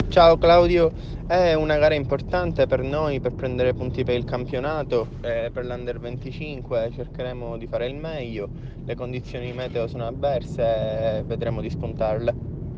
Interviste al Rally Due Valli
Interviste pre-gara